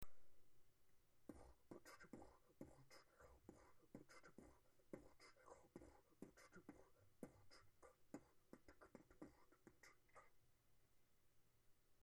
не знаю как у тебя а у меня в твоих записях ничево не слышно dry
микрофон нормальный вроде
Бочка тока норм идёт вроде, остальные звуки не понятны абсолютно, что это за звуки я не понял. то шипение то хендклеп неотработанный.Тренеруйся, всё получится! smile
Там нету бочки)